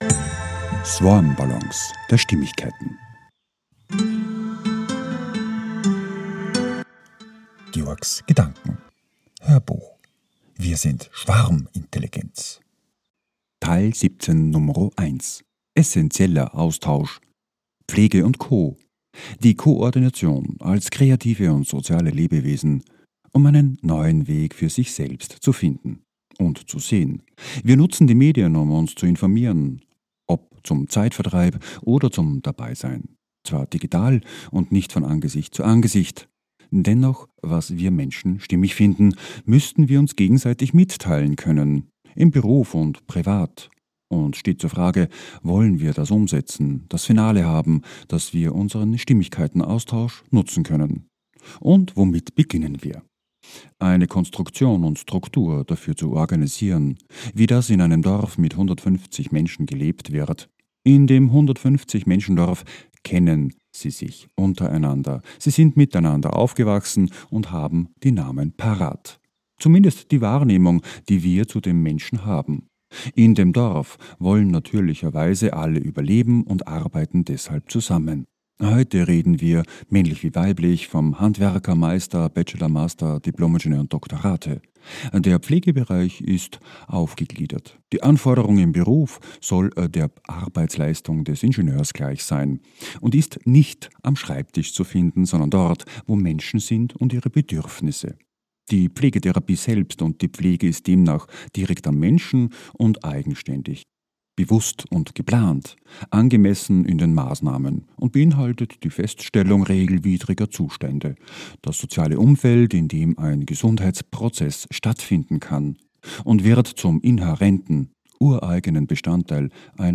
HÖRBUCH - 017.1 - WIR SIND SCHWARMINTELLIGENZ - Im essentiellen Austausch - PFLEGE & CO